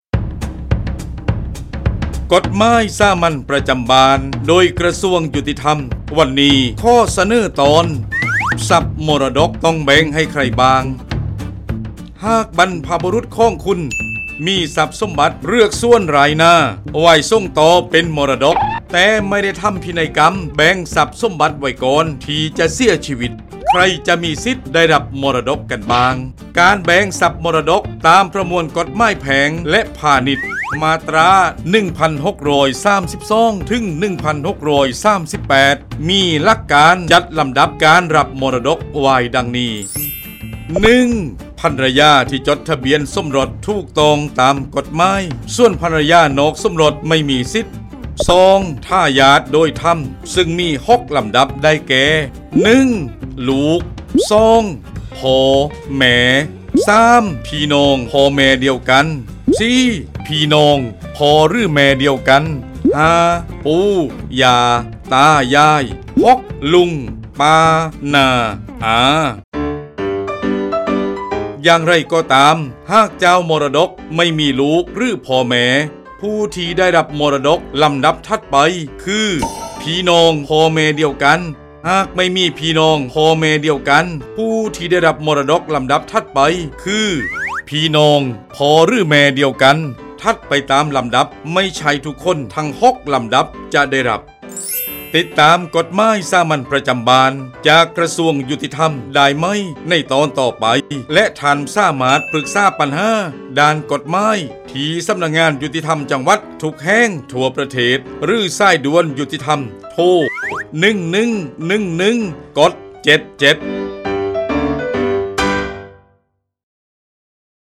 กฎหมายสามัญประจำบ้าน ฉบับภาษาท้องถิ่น ภาคใต้ ตอนทรัพย์มรดกต้องแบ่งให้ใครบ้าง
ลักษณะของสื่อ :   คลิปเสียง, บรรยาย